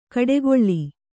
♪ kaḍegoḷḷi